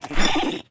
Npc Tofu Kick Sound Effect
npc-tofu-kick.mp3